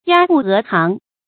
鴨步鵝行 注音： ㄧㄚ ㄅㄨˋ ㄜˊ ㄒㄧㄥˊ 讀音讀法： 意思解釋： 鴨和鵝行八字步，因用以形容人走路遲緩搖晃的樣子。